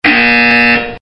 buzzer